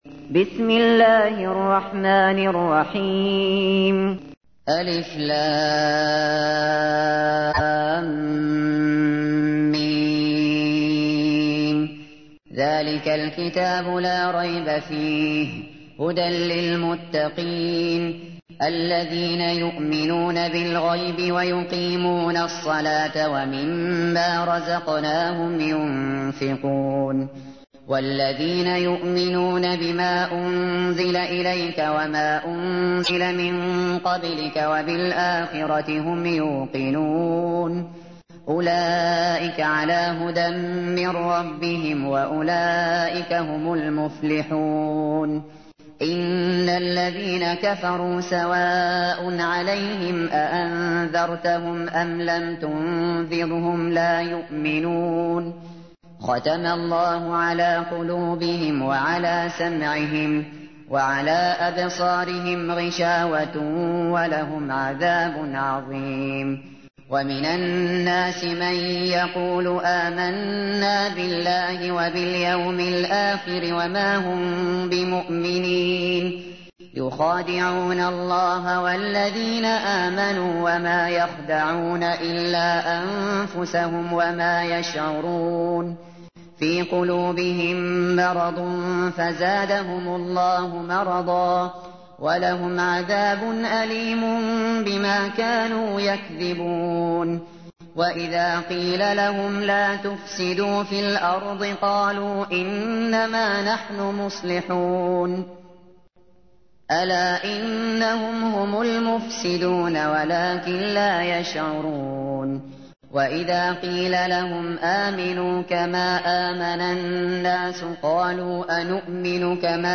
تحميل : 2. سورة البقرة / القارئ الشاطري / القرآن الكريم / موقع يا حسين